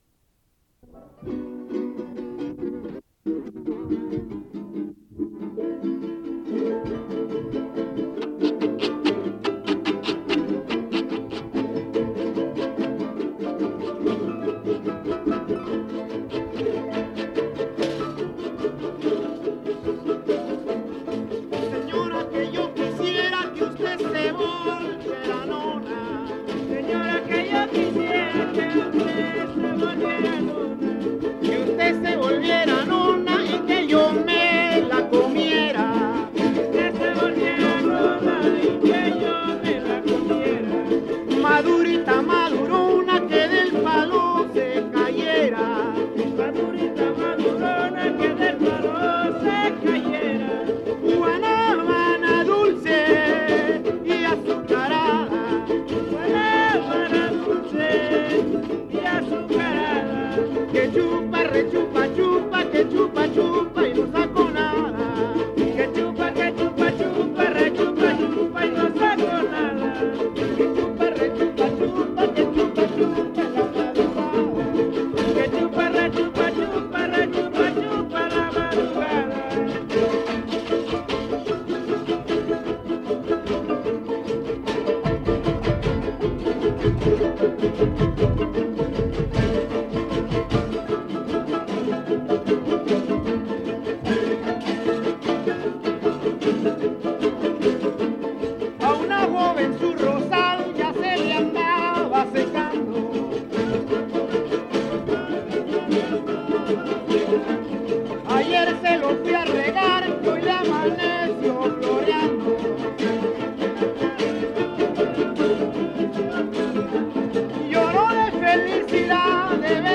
• Mono Blanco (Grupo musical)
Noveno Encuentro de jaraneros